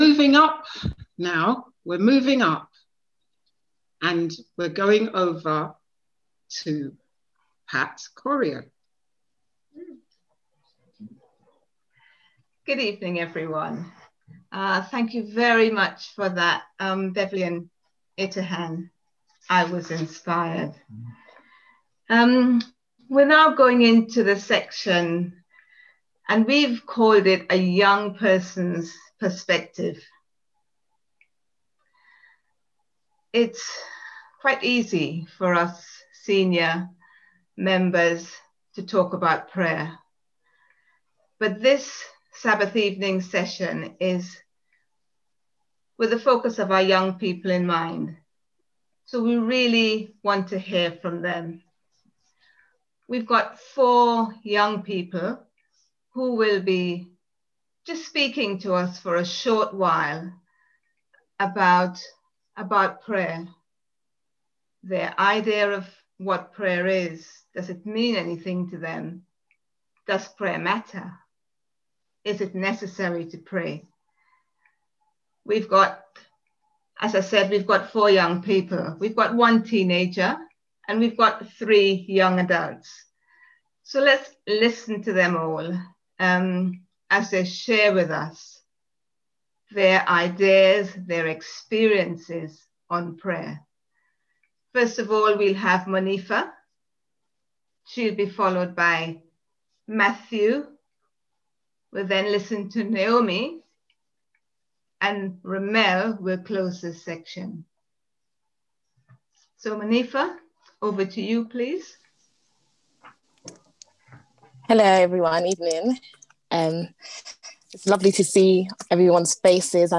on 2021-02-08 - Redeeming Love Children & Youth Prayer Service 6.2.21